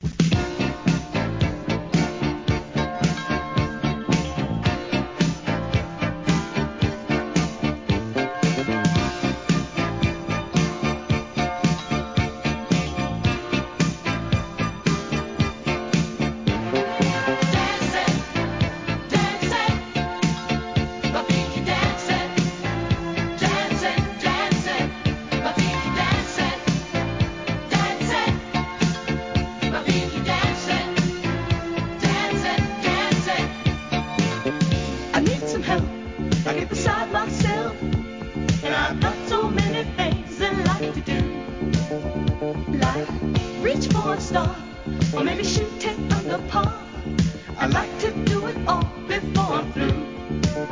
SOUL/FUNK/etc... 店舗 ただいま品切れ中です お気に入りに追加 1979年、哀愁のタップDISCO!!